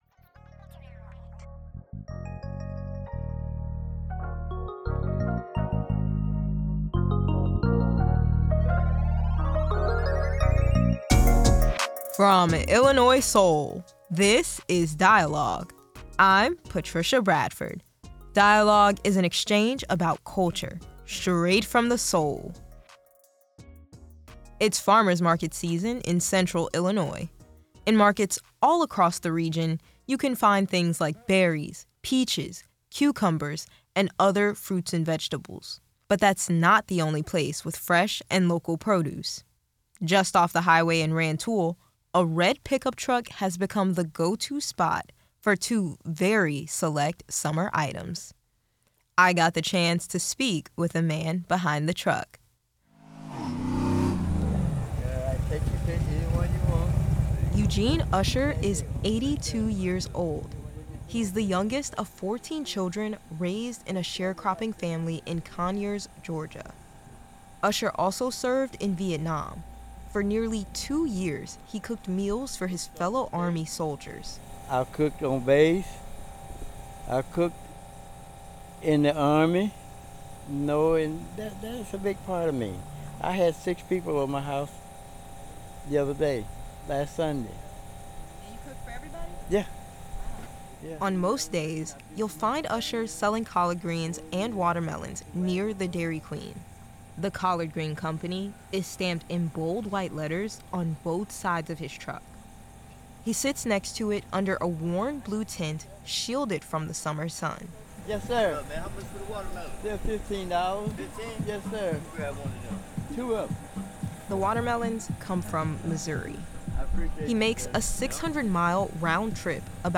Hear voices from across Central Illinois share stories of community and leadership.